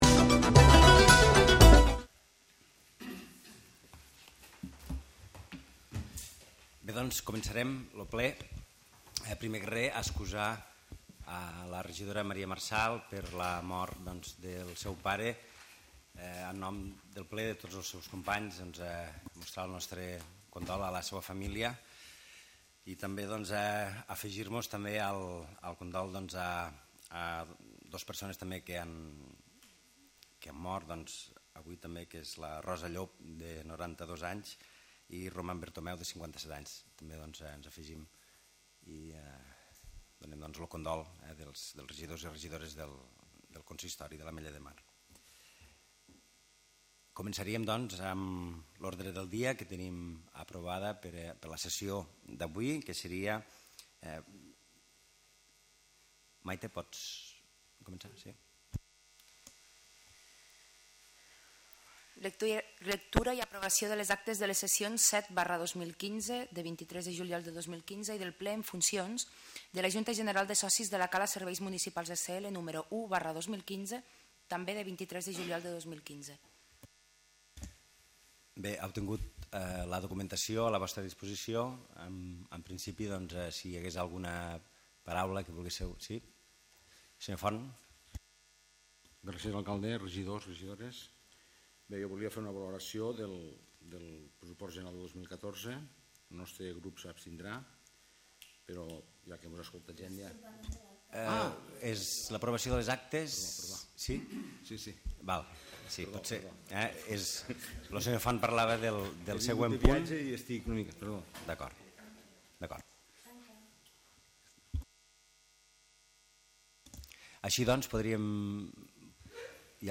Sessió Plenària de l'Ajuntament de l'Ametllademar del 30 de setembre, on es van tractar 9 punts en l'ordre del dia